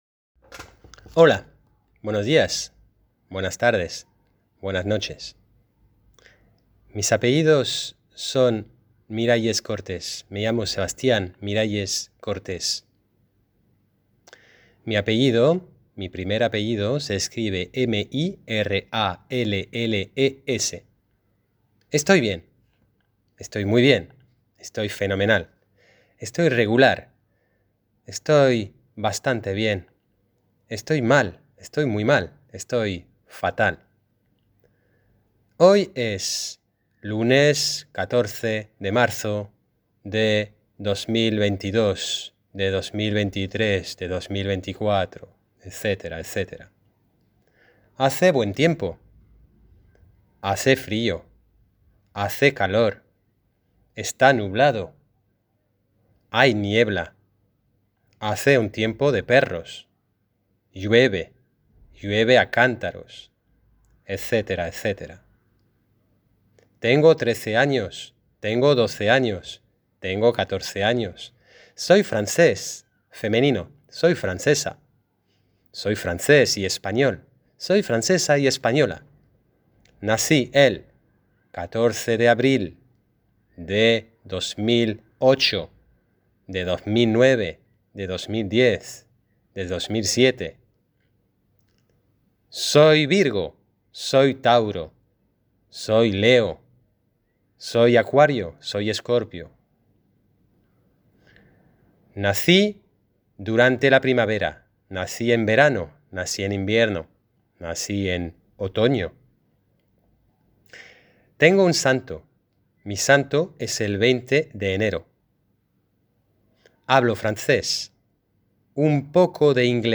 Un ejemplo (profesor)...